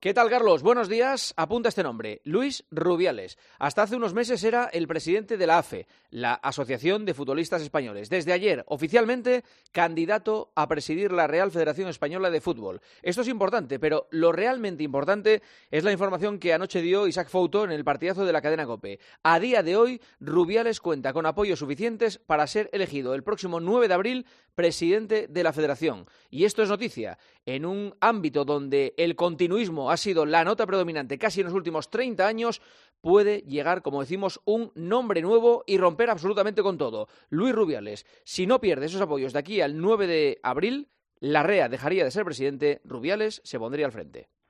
El comentario del director de 'El Partidazo' de COPE en 'Herrera en COPE'